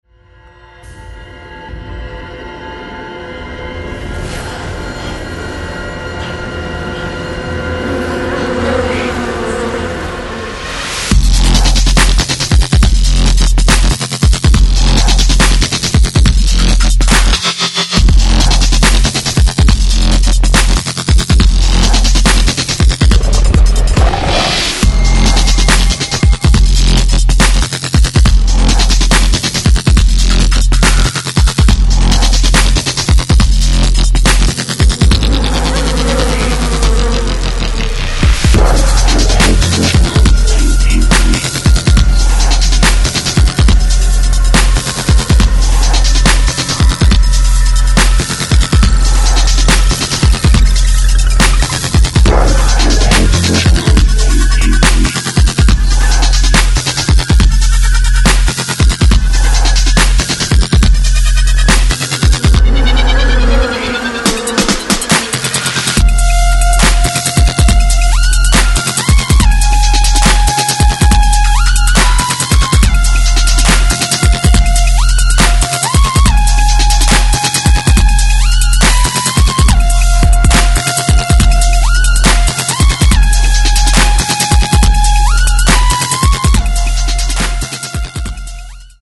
Styl: Dub/Dubstep